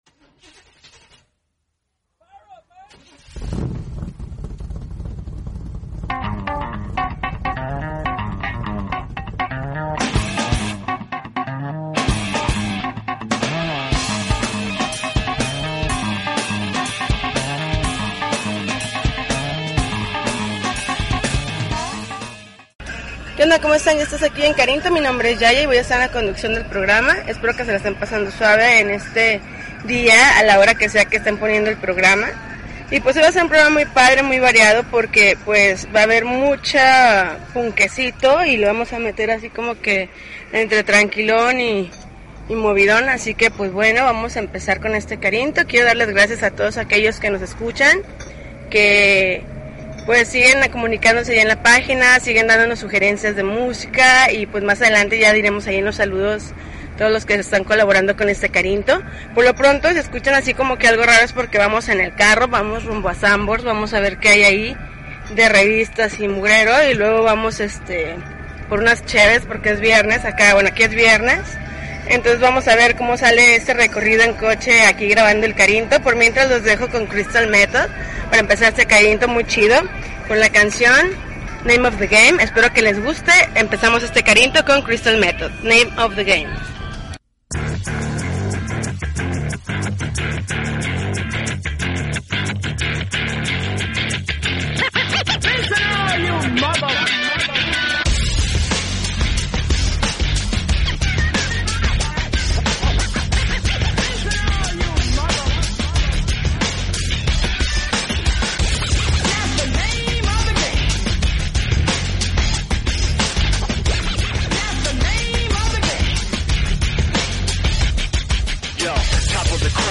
August 19, 2012Podcast, Punk Rock Alternativo